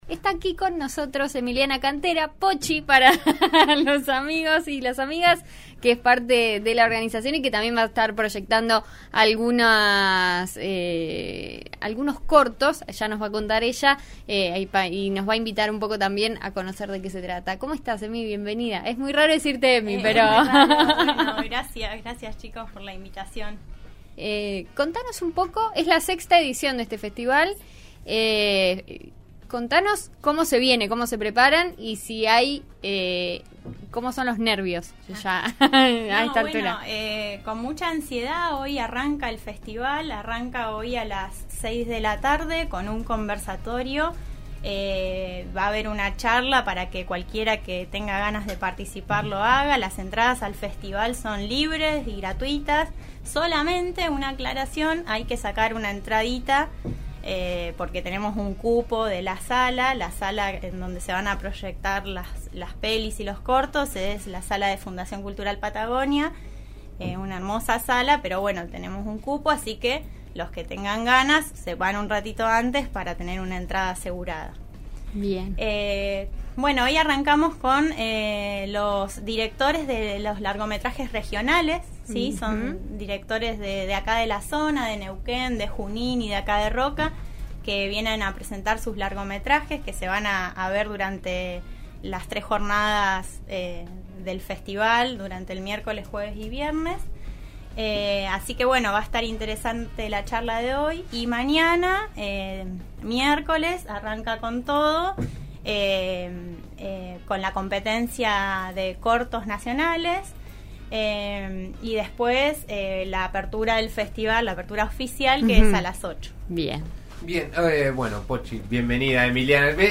En eso estamos de RN Radio recibió en el estudio